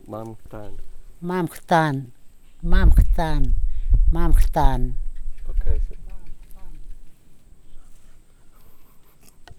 Brem, Qkuan Kambuar dialect
digital wav file recorded on Zoom H2n digital recorder
Tokain, Madang Province, Papua New Guinea